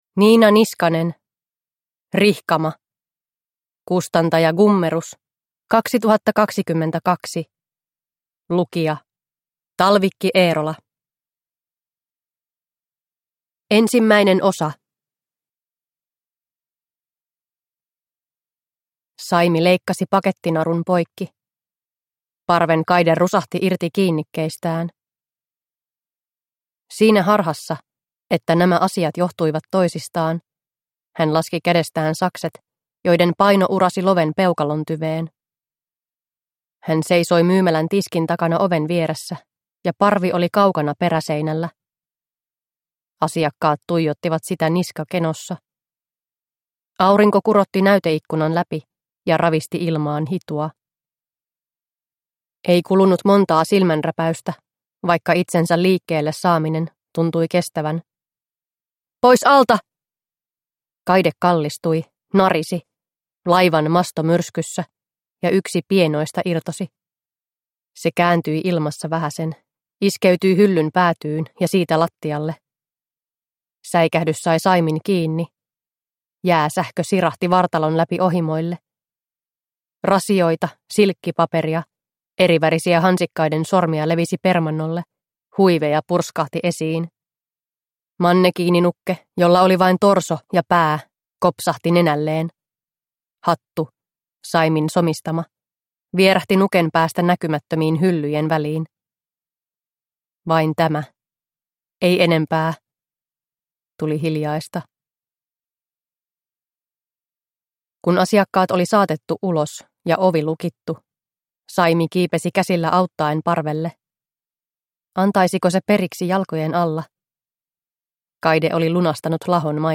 Rihkama – Ljudbok – Laddas ner